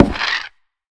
creaking_heart_hit1.ogg